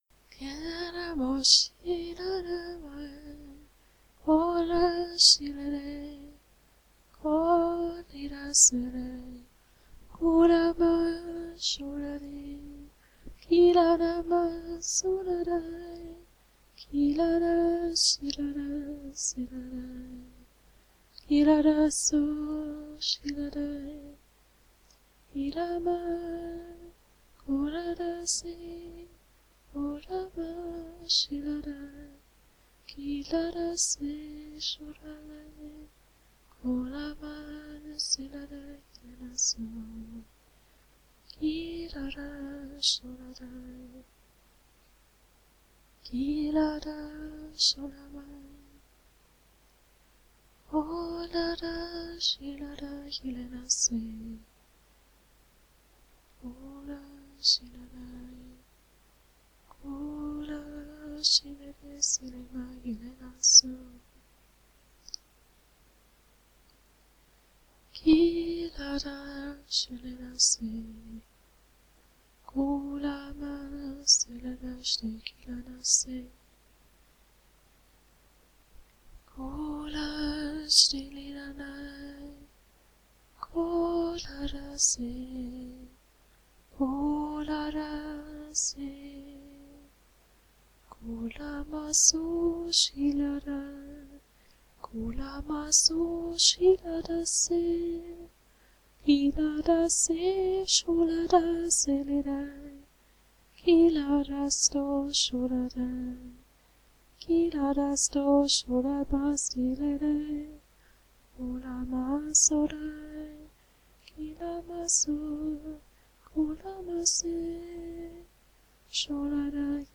Dieses Lied (siehe mp3-Aufnahme und Video) ist spontan entstanden.
Gesungen in Sprachengesang und die Botschaft auf Deutsch.
Die Botschaft beginnt ab 2:25 Minuten.